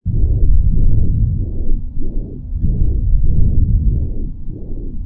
rumble_nomad.wav